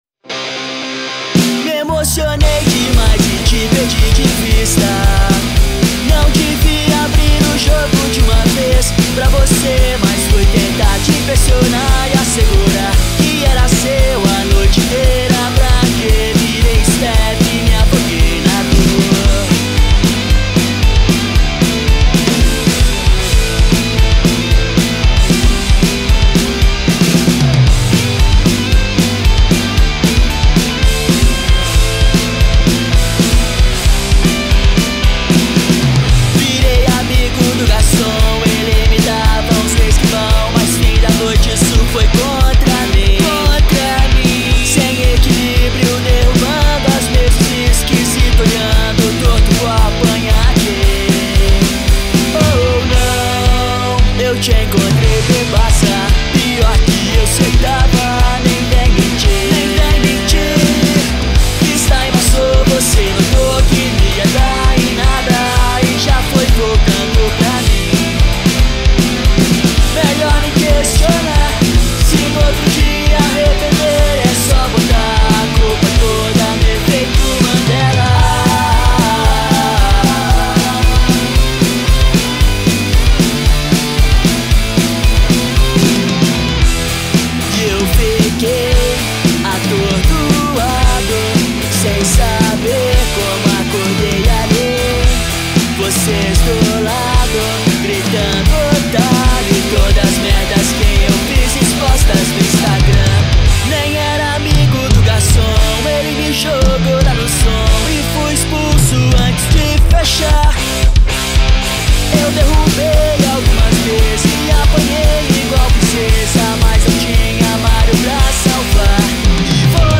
2024-10-23 19:06:54 Gênero: Rock Views